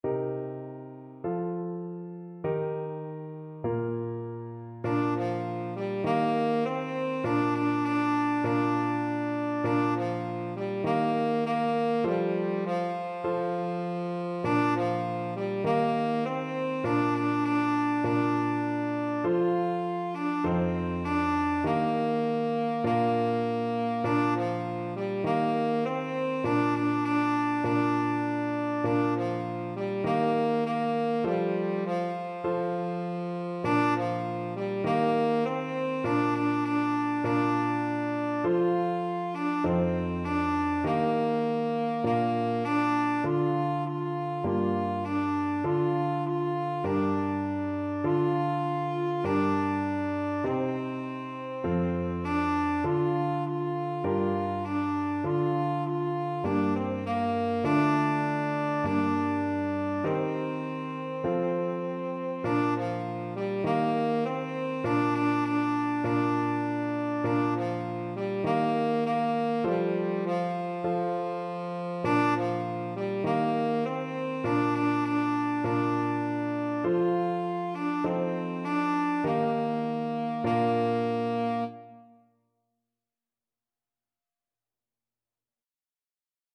4/4 (View more 4/4 Music)
F4-F5
Gospel & Spiritual Tenor Sax